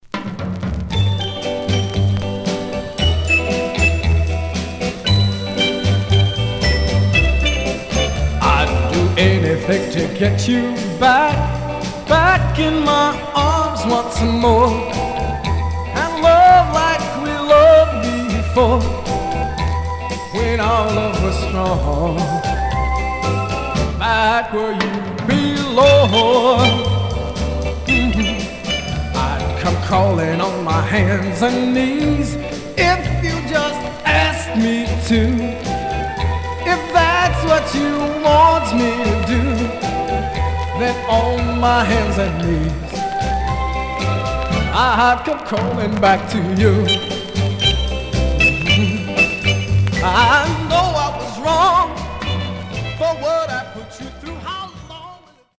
Surface noise/wear Stereo/mono Mono
Soul